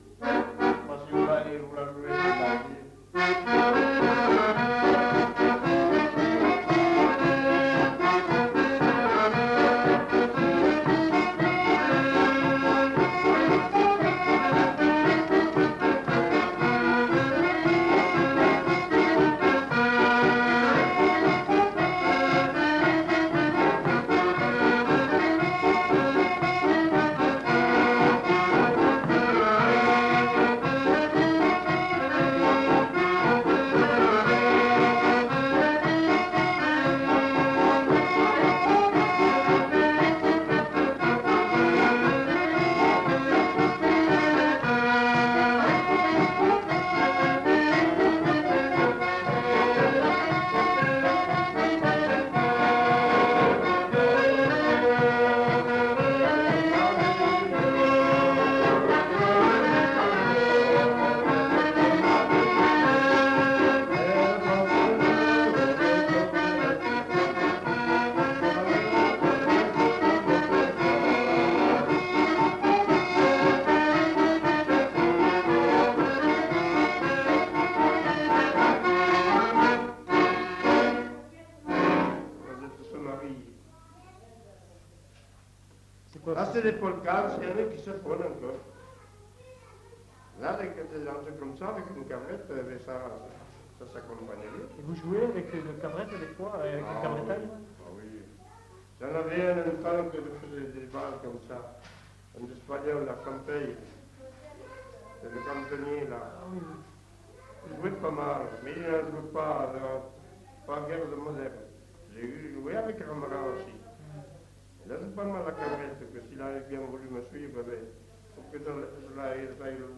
Lieu : La Capelle (lieu-dit)
Genre : morceau instrumental
Instrument de musique : accordéon chromatique